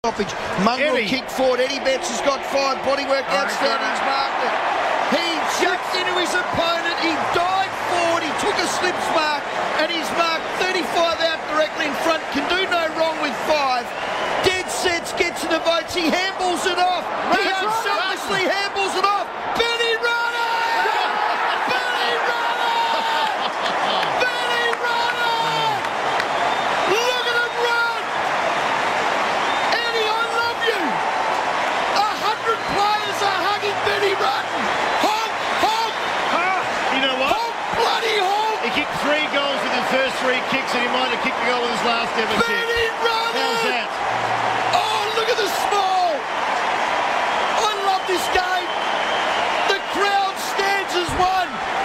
go nuts on FIVEaa's call of Ben Rutten's magical goal late in his final AFL game against the Saints